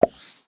mixkit-long-pop.mp3